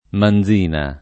[ man z& na ]